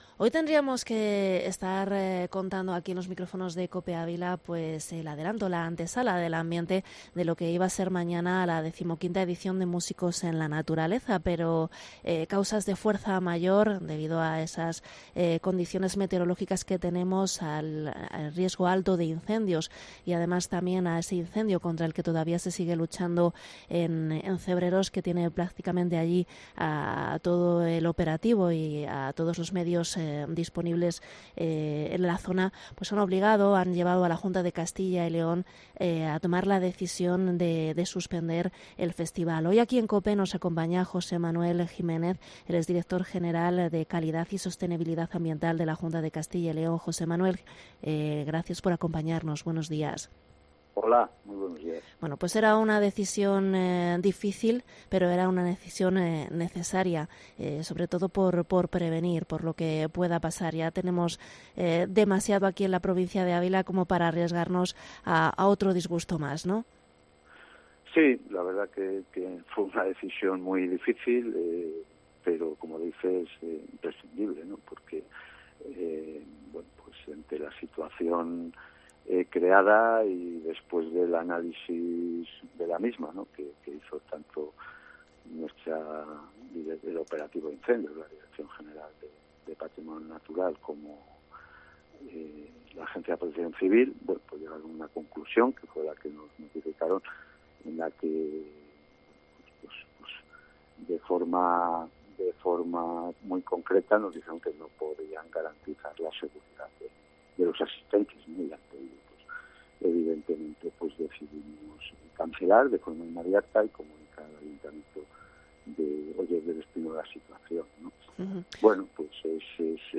Entrevista al director general de Calidad y Sostenibilidad Ambiental, José Manuel Jiménez